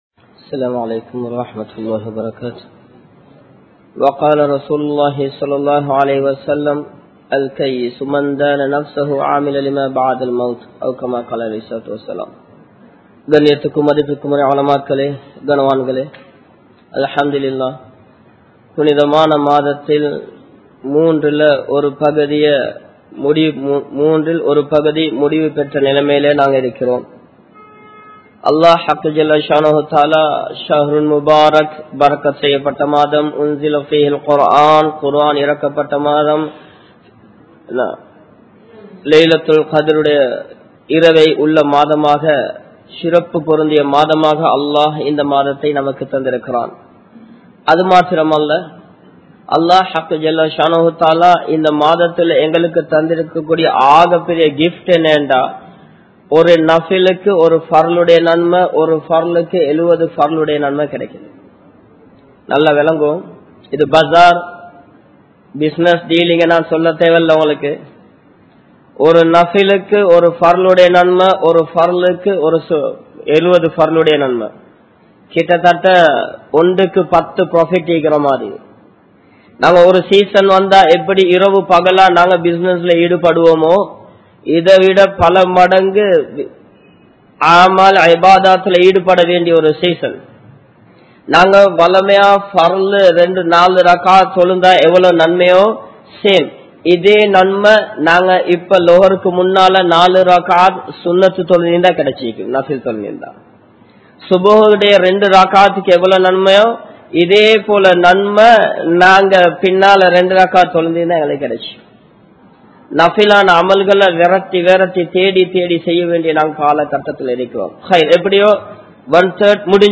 Noanpum Saharum Indraya Muslimkalum (நோன்பும் ஷஹரும் இன்றைய முஸ்லிம்களும் ) | Audio Bayans | All Ceylon Muslim Youth Community | Addalaichenai
Samman Kottu Jumua Masjith (Red Masjith)